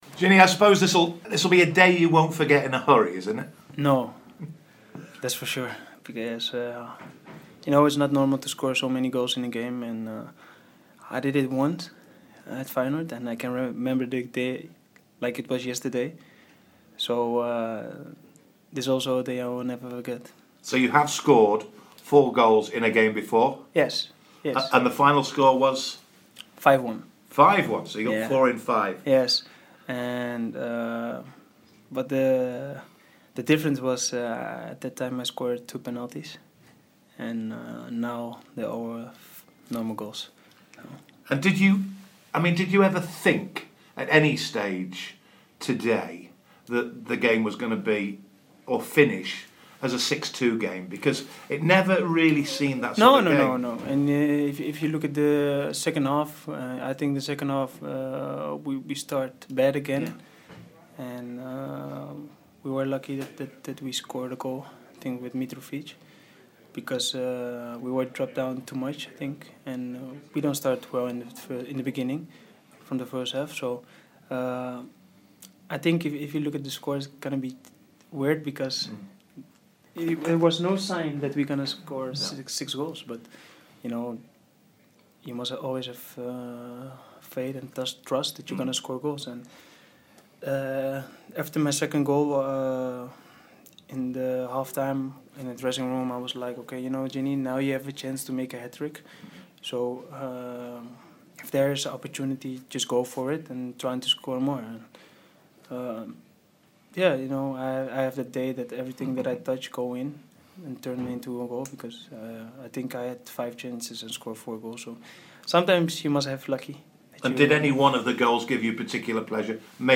REACTION: Georginio Wijnaldum speaks to BBC Newcastle